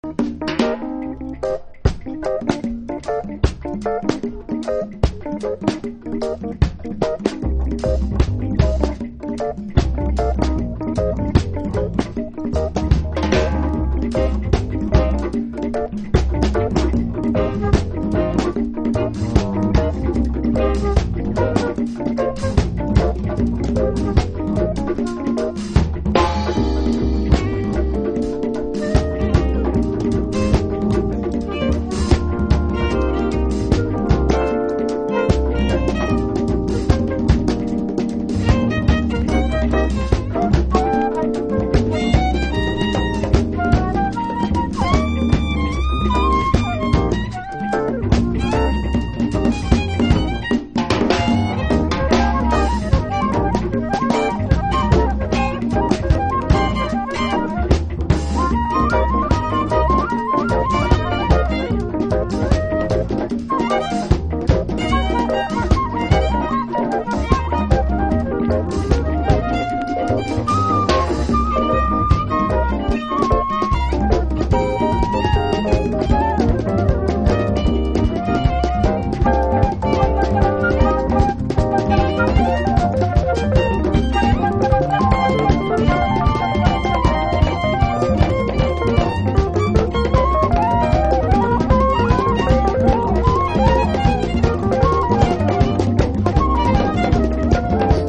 Jazz / Crossover
名曲のリメイクを含めながら70年代ブラックジャズの精神性も引き継いだサウンド。